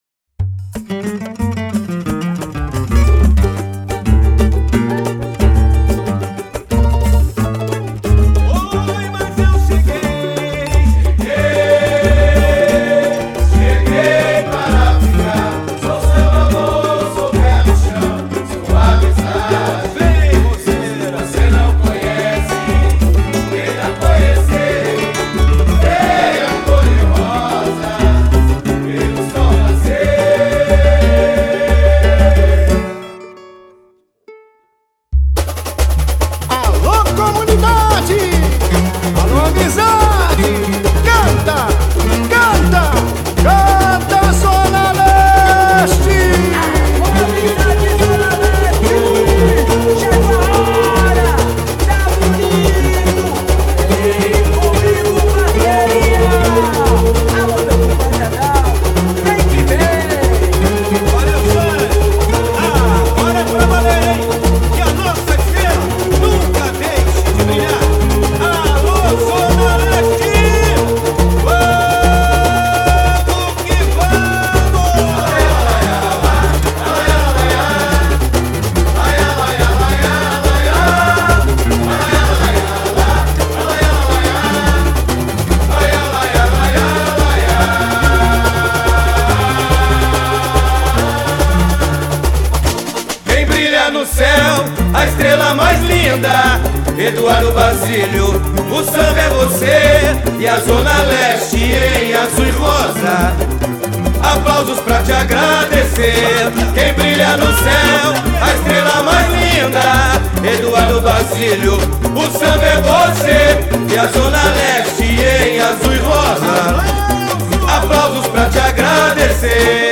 samba-enredo